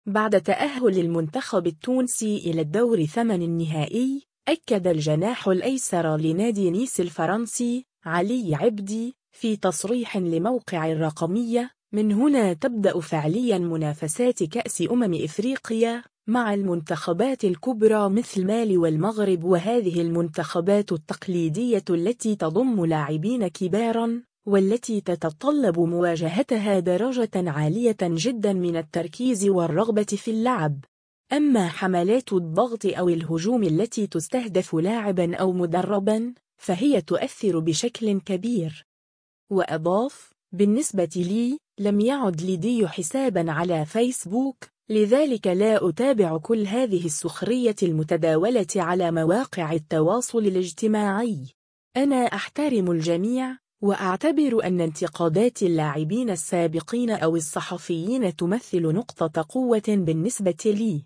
بعد تأهّل المنتخب التونسي إلى الدور ثمن النهائي، أكد الجناح الأيسر لنادي نيس الفرنسي، علي عبدي، في تصريح لموقع الرقمية : «من هنا تبدأ فعليًا منافسات كأس أمم إفريقيا، مع المنتخبات الكبرى مثل مالي والمغرب وهذه المنتخبات التقليدية التي تضم لاعبين كبارًا، والتي تتطلّب مواجهتها درجة عالية جدًا من التركيز والرغبة في اللعب. أمّا حملات الضغط أو الهجوم التي تستهدف لاعبًا أو مدرّبًا، فهي تؤثّر بشكل كبير».